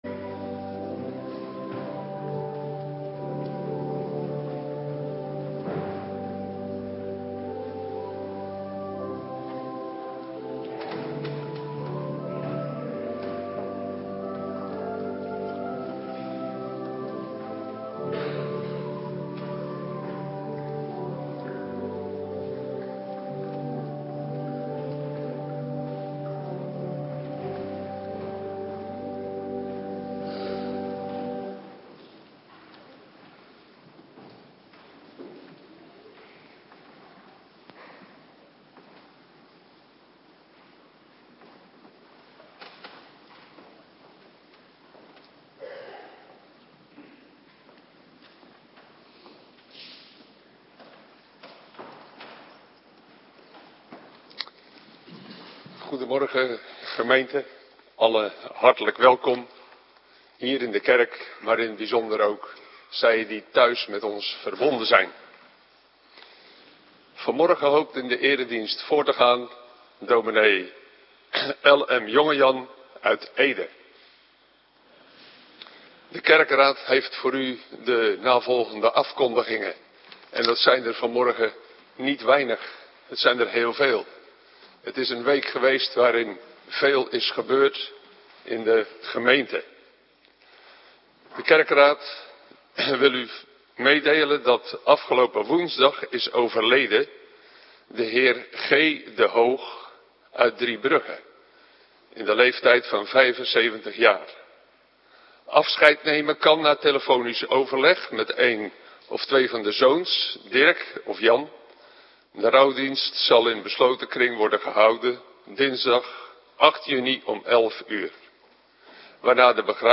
Morgendienst Voorbereiding Heilig Avondmaal - Cluster 2
Locatie: Hervormde Gemeente Waarder